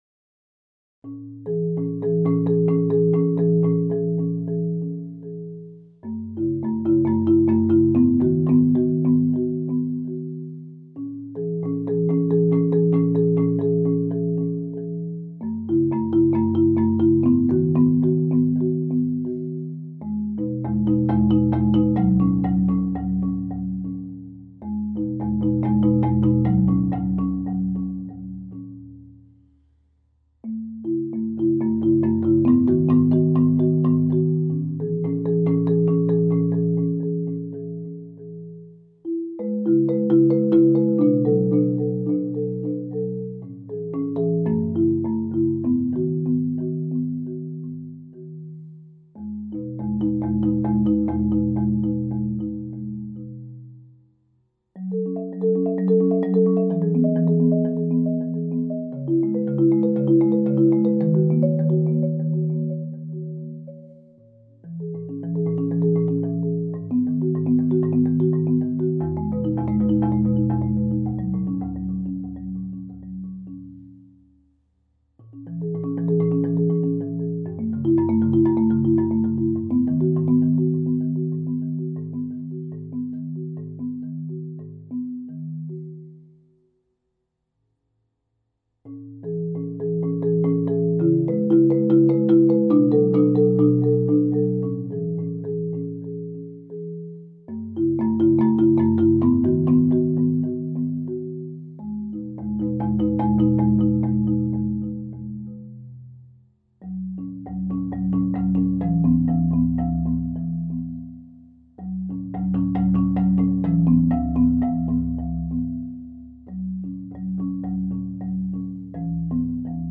Slow Marimba Cue